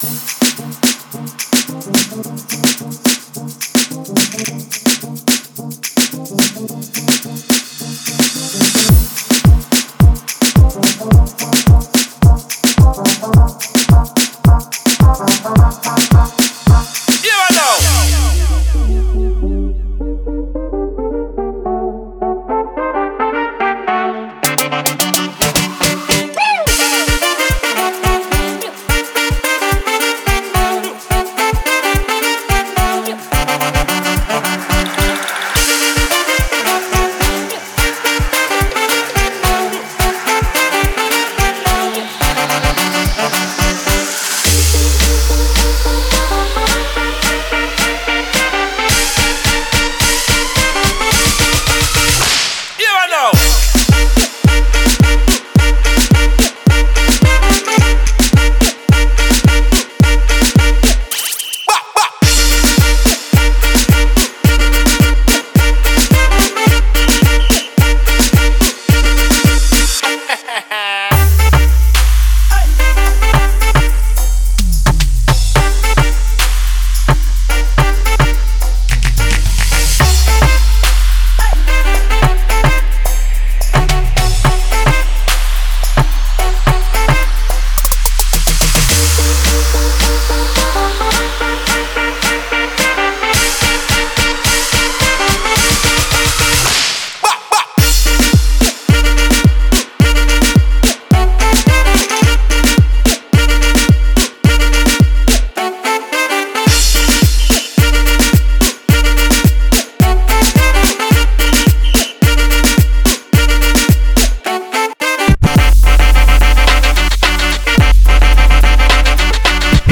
это энергичная композиция в жанре электронной музыки